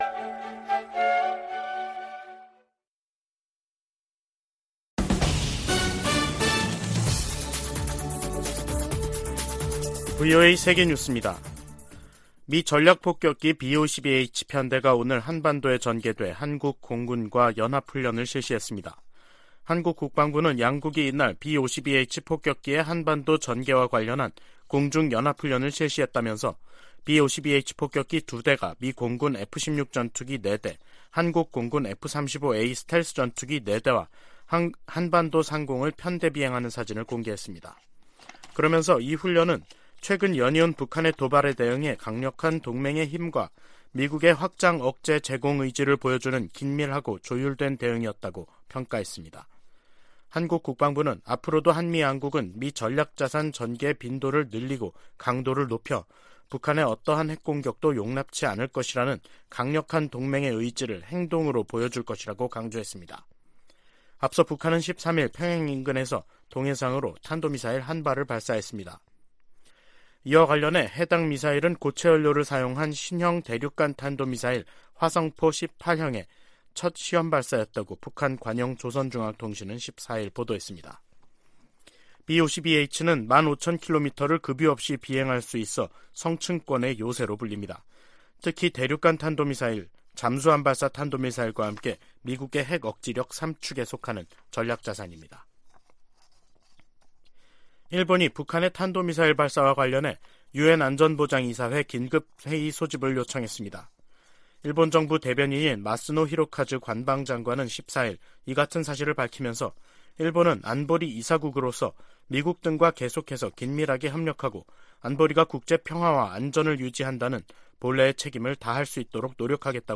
VOA 한국어 간판 뉴스 프로그램 '뉴스 투데이', 2023년 4월 14일 2부 방송입니다. 북한은 13일 발사한 '화성포-18형'이 고체연료를 사용한 신형 대륙간탄도미사일(ICBM)이라고 다음날인 14일 밝혔습니다. 북한이 핵 공격을 감행하면 김정은 정권의 종말을 초래할 것이라고 미국과 한국 국방당국이 경고했습니다. 북한이 우주 사업을 적극 추진하겠다고 밝힌 데 대해 미 국무부는 안보리 결의 위반 가능성을 지적했습니다.